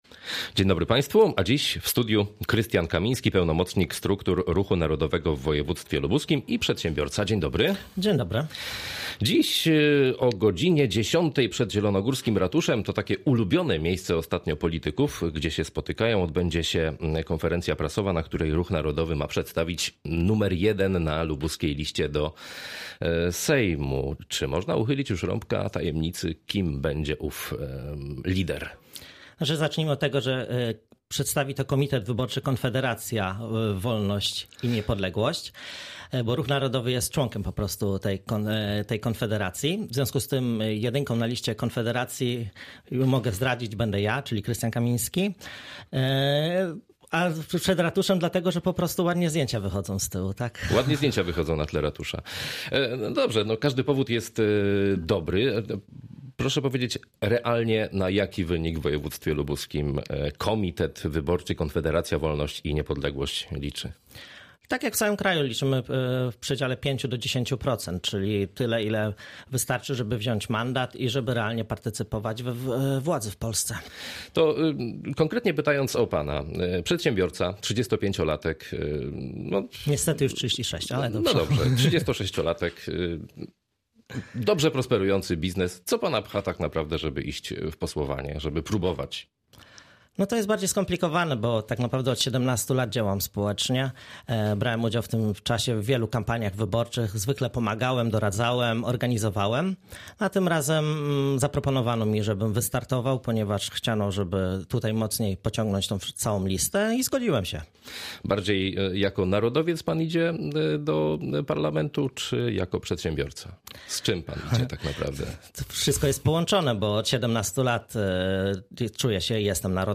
poranny-gosc-krystian-kaminski-konfederacja-wolnosc-i-niepodleglosc.mp3